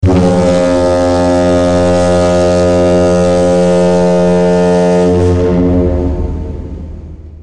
Звуки цензуры
Цензура: гудок парохода вместо мата в роликах